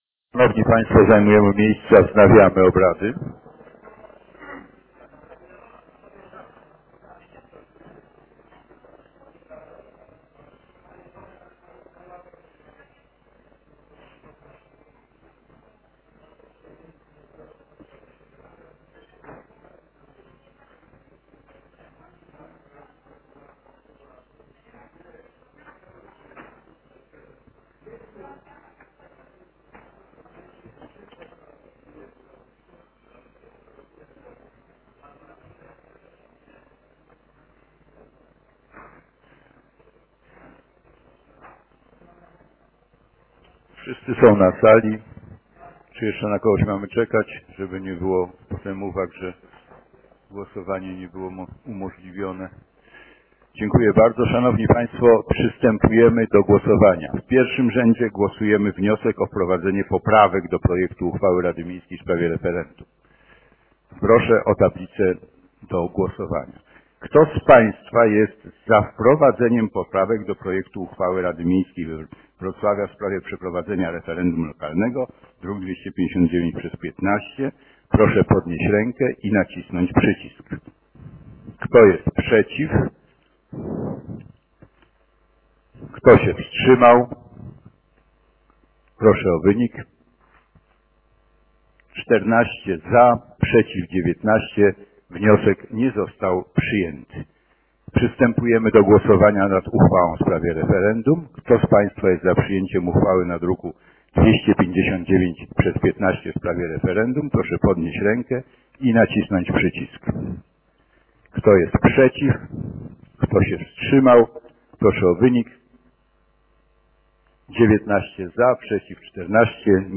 Sesja Rady Miejskiej Wrocławia nr XIII dnia 9 lipca 2015 r., godz. 12:00 - Sesje Rady - Biuletyn Informacji Publicznej Urzędu Miejskiego Wrocławia
Zapis dźwiękowy Sesji